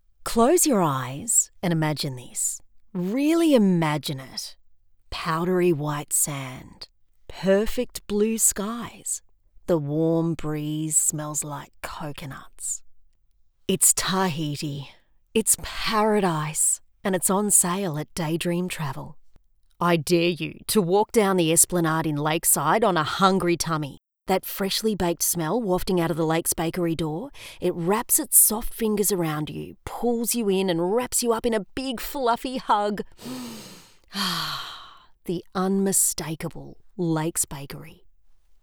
• Conversational
• Natural
• Natural, warm,
• Mic: Rode NT1-A